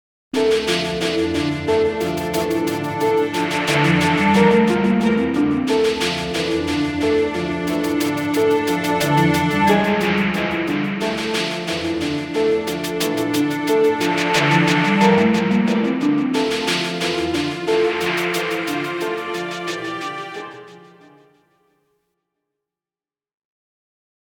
Fantasy track for transitions & stingers.
Hybrid fantasy track for transitions & stingers.